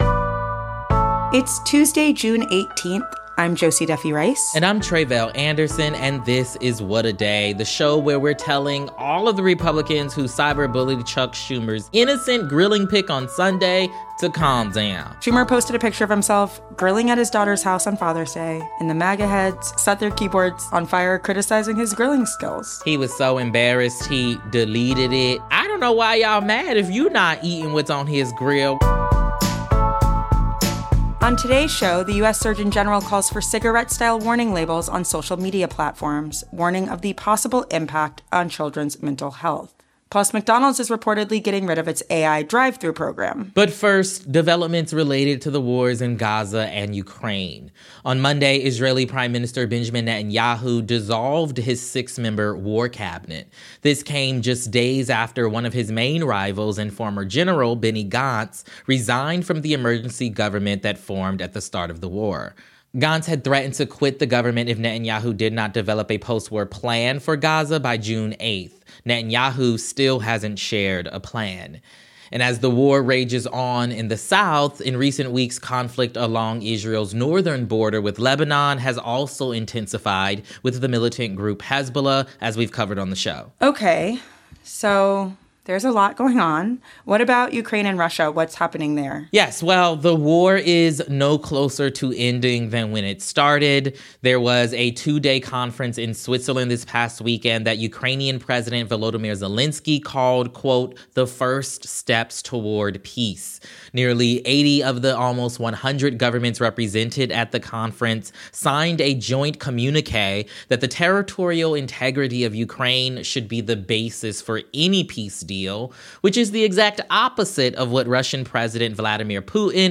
Ben Rhodes, former U.S. deputy national security advisor and co-host of ‘Pod Save The World,’ talks about what these developments mean for both wars.